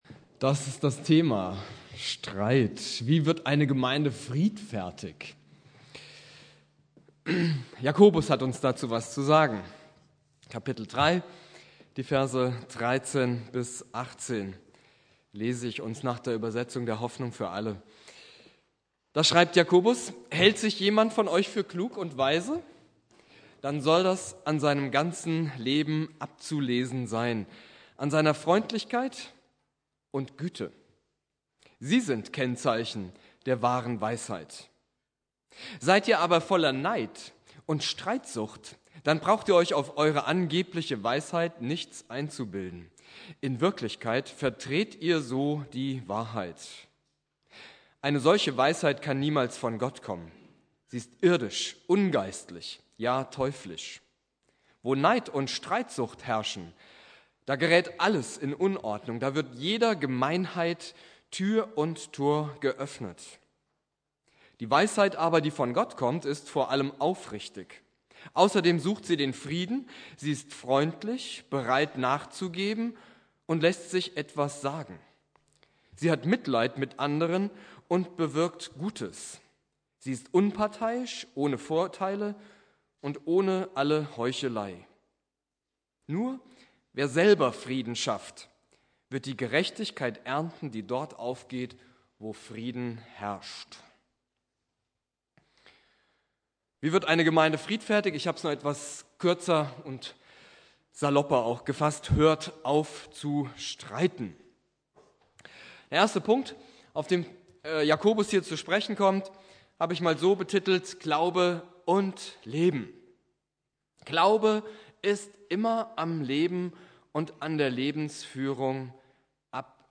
Thema: Wie wird eine Gemeinde friedfertig? Inhalt der Predigt: Hört auf zu streiten!